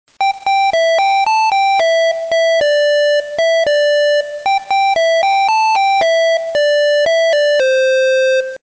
NAST　小型前後進メロディーアラーム